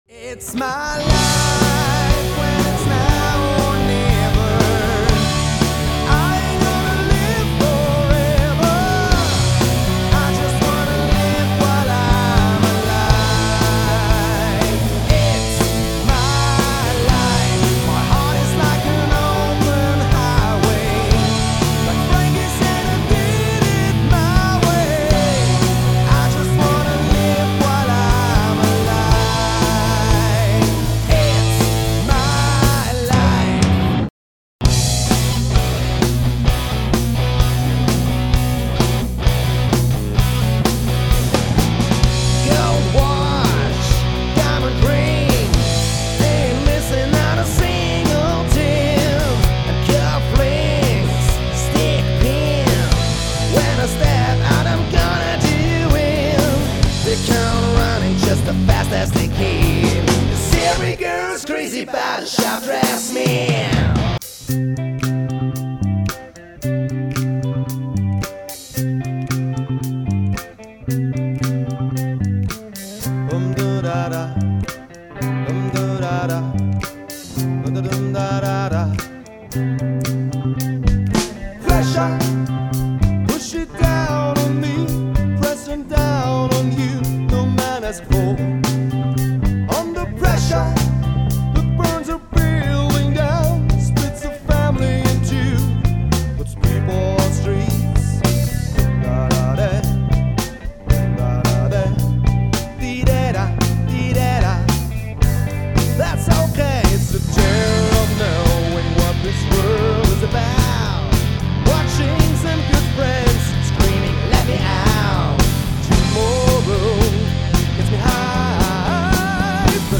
Quintett
Rockband
Machen sie sich auf Klassiker der Rockgeschichte gefasst